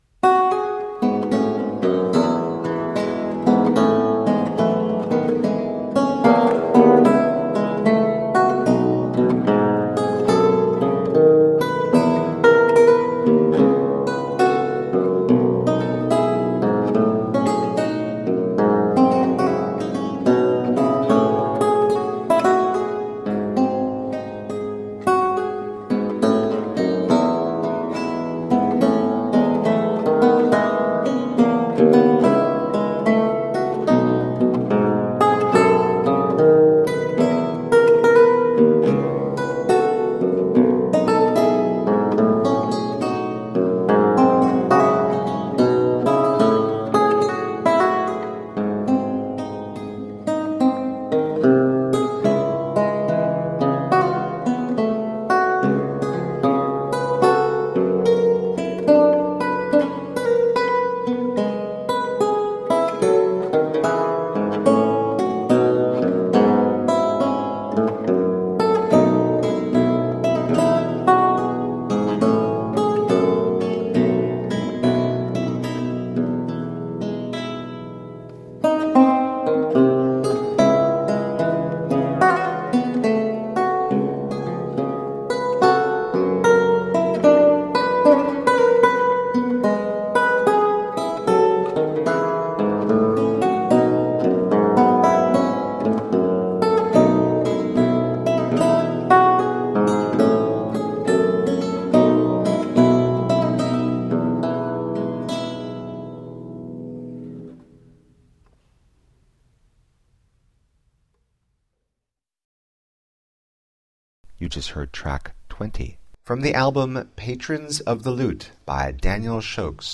A feast of baroque lute.
Classical, Baroque, Instrumental